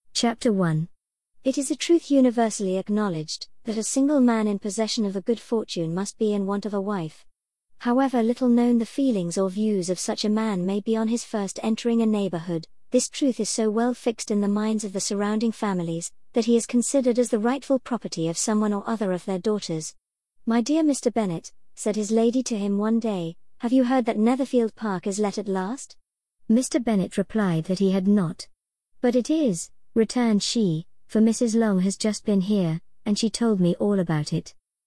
We selected the Neural voice for the three following services: Microsoft Azure, Google Gemini, Amazon Polly while selecting the standard voices for Eleven Labs’ and OpenAI.
• Amazon Polly Sample:
Amazon_Polly_clip.mp3